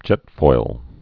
(jĕtfoil)